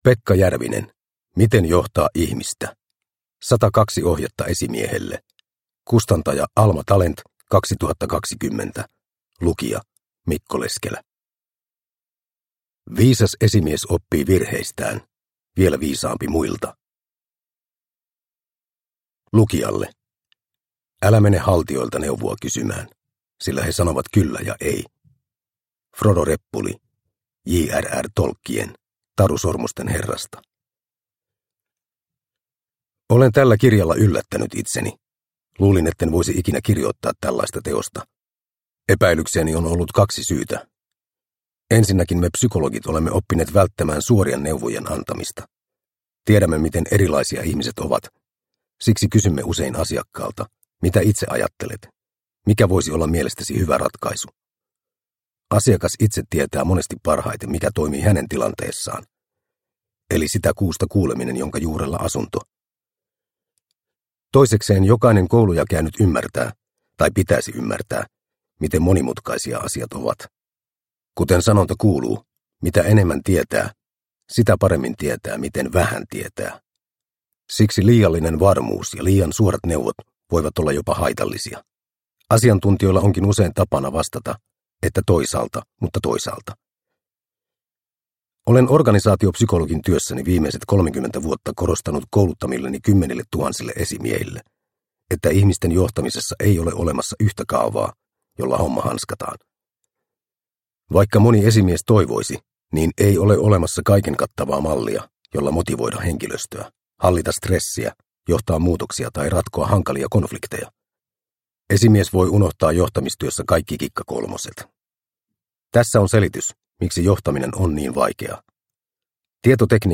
Miten johtaa ihmistä – Ljudbok – Laddas ner